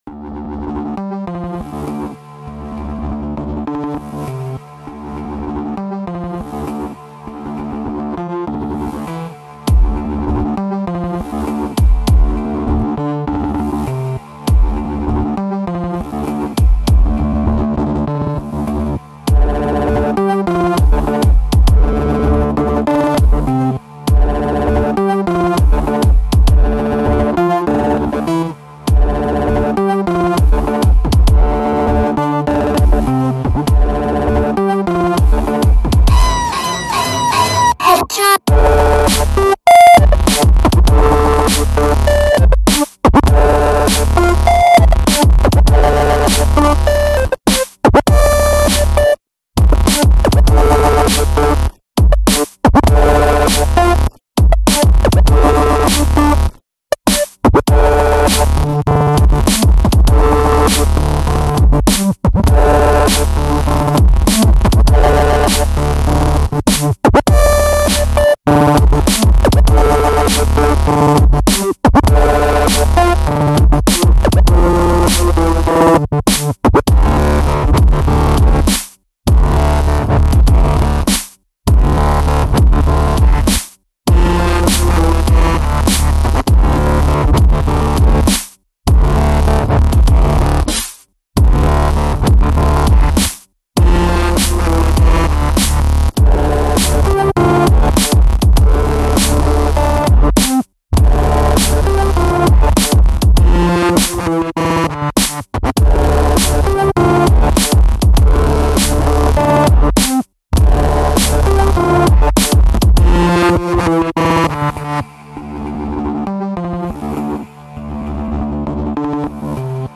I mixed punk/metal/electronic/glitch together.